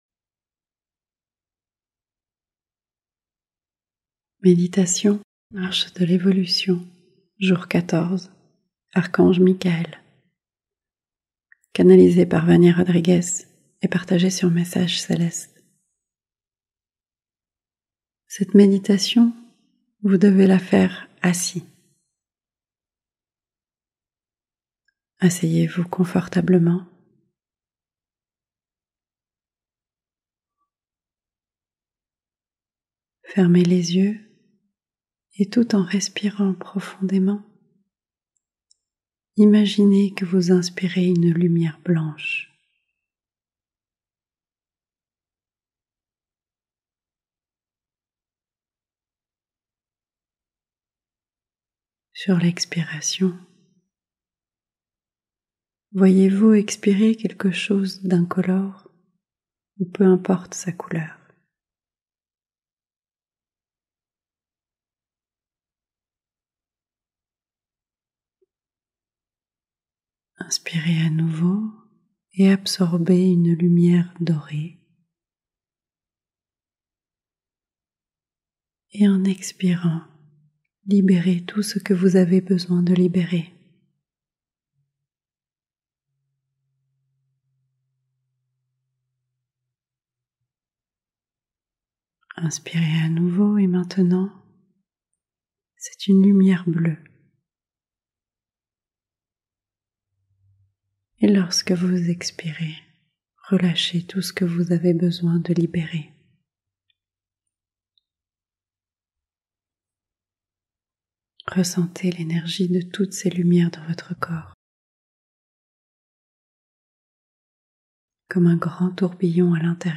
Jour 14 - Méditation - sans_pub